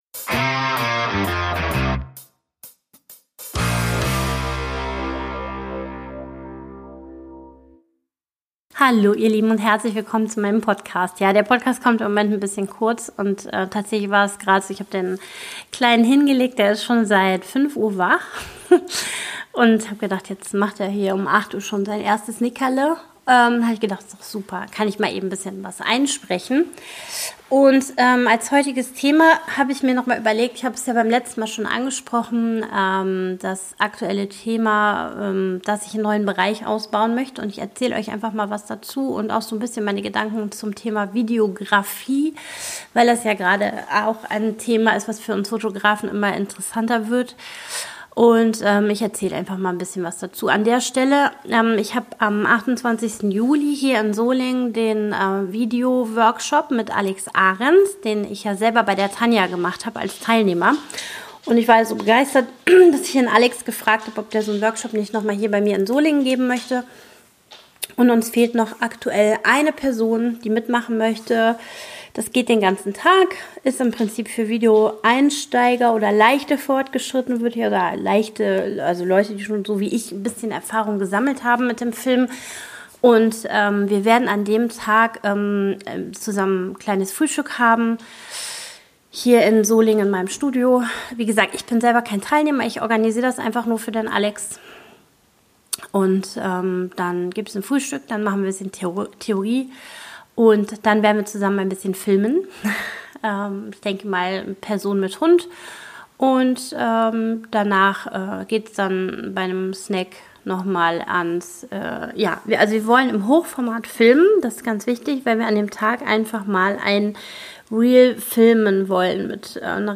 Viel planloses Gequatsche und ehrliches Gedankenchaos ist wohl eher das, was Ihr hier zu hören bekommt.